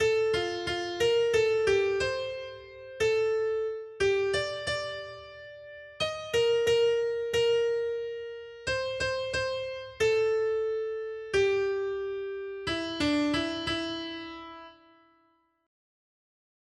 Noty Štítky, zpěvníky ol160.pdf responsoriální žalm Žaltář (Olejník) 160 Skrýt akordy R: Pojďte se poklonit Pánu! 1.